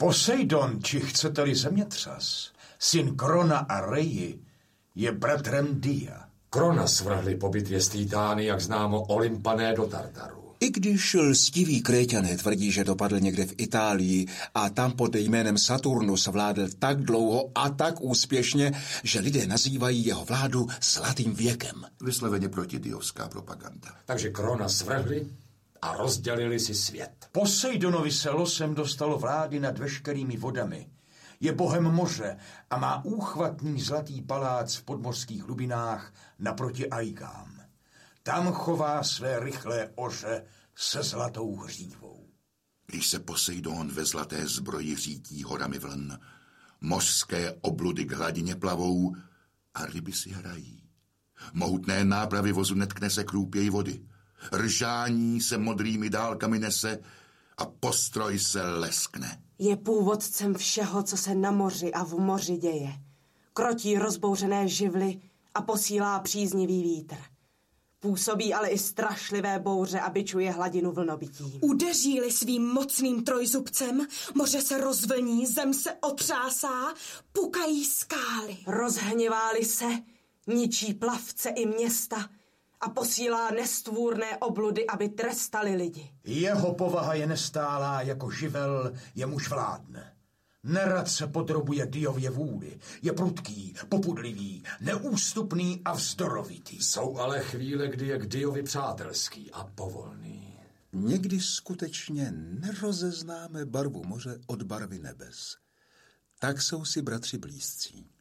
Životy bohů audiokniha
Ukázka z knihy